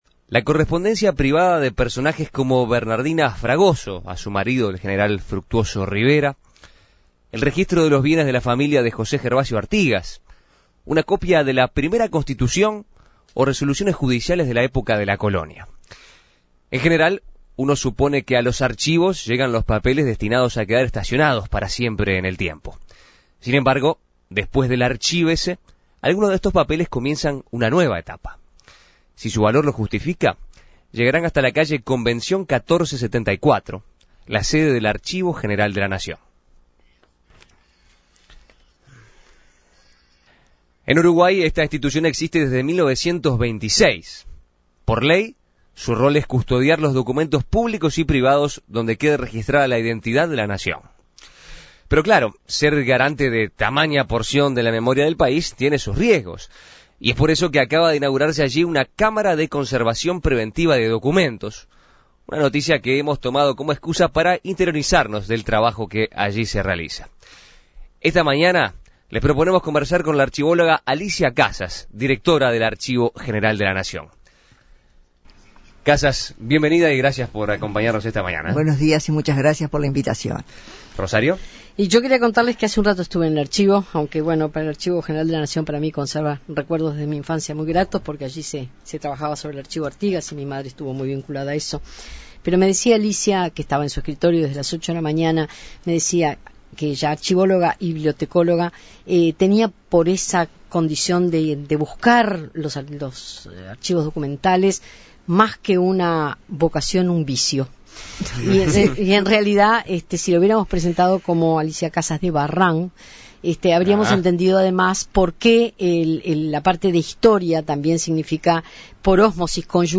En el Archivo General de la Nación se acaba de inaugurar una cámara de conservación preventiva de documentos. Con esta innovación es posible preservar documentos de la época colonial que hacen a la historia de nuestro país. Para conocer más sobre la función de un archivo de este tipo y de la importancia que tiene para la sociedad, En Perspectiva dialogó con su directora, la archivóloga Alicia Casas.